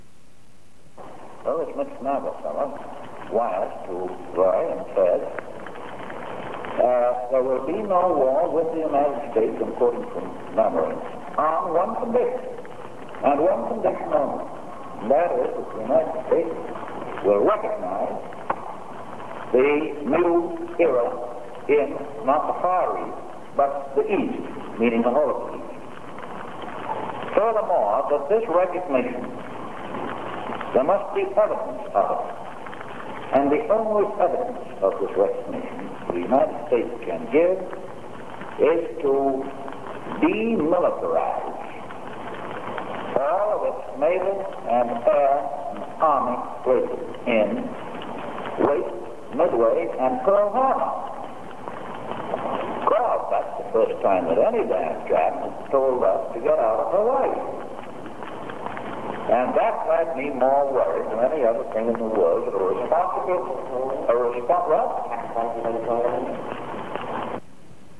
President Roosevelt speaks about an ultimatum given by the Japanese demanding that the U.S. pull out of Wake Island, Midway Island and Pearl Harbor in Hawaii. The conversation is a segment from recordings made secretly in the Oval Office by FDR.
Recorded at White House Oval Office, Washington D.C., Oct. 1940.